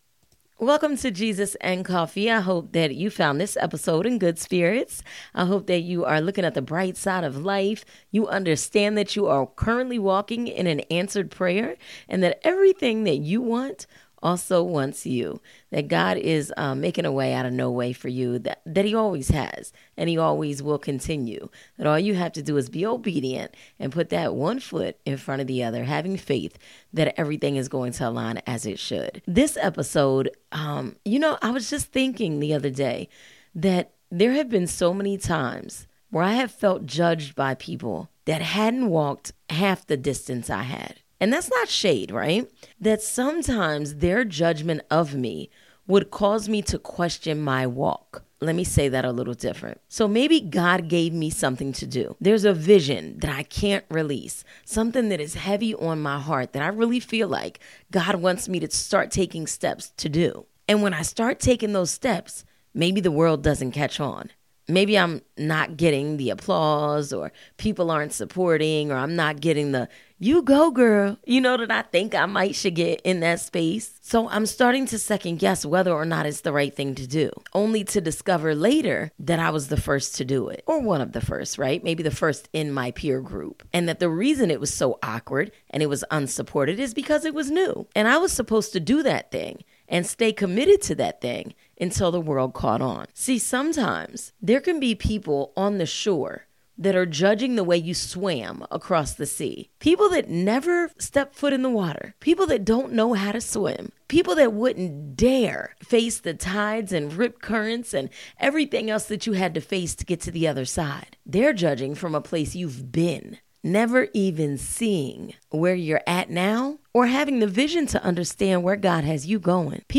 Having a conversation with a friend; designed to help sprinkle some upbeat positivity in the listeners day. There will be storytelling, the occasional guest interview, and biblical reference to help set the tone.